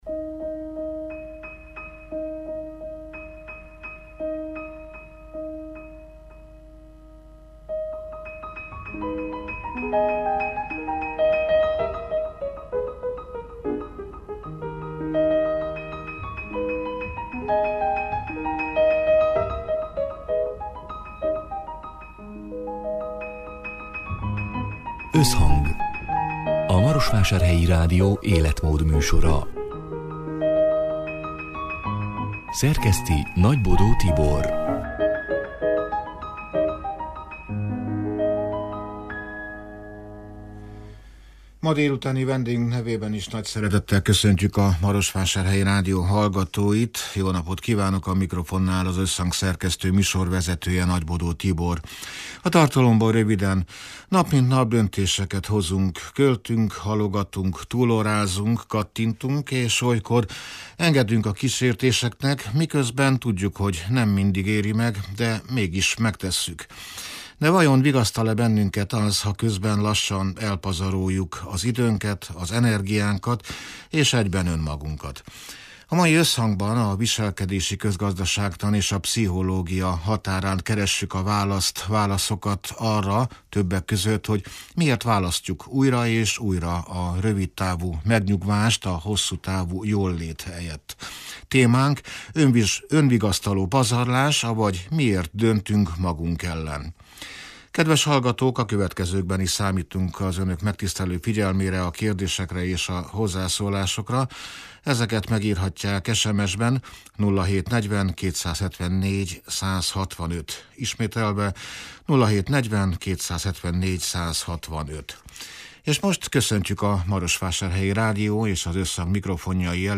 (elhangzott: 2026. március 18-án, szerdán délután hat órától élőben)